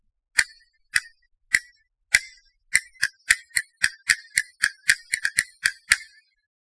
Castanets
VocalsCastanetsApplause_IBM__Perc.mp3